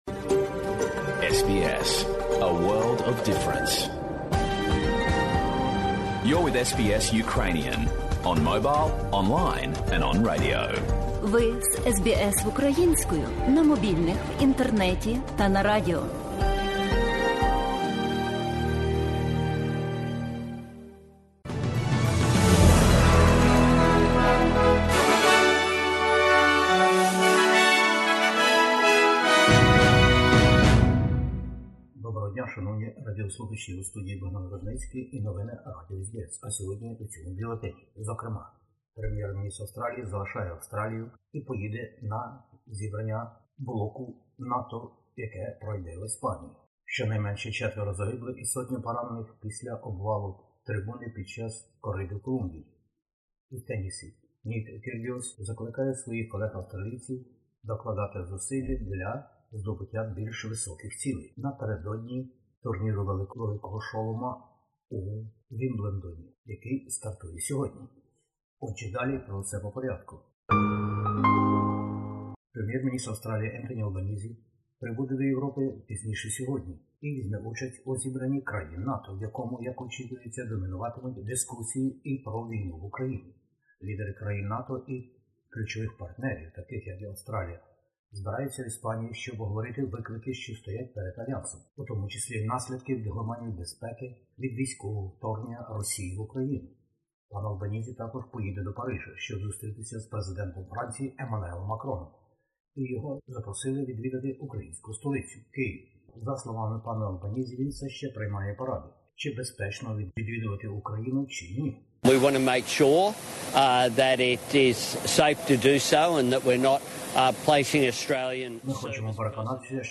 Бюлетень SBS новин українською мовою. Прем'єр-міністр Австралії прибуде до Європи на зустріч країн блоку НАТО та партнерів.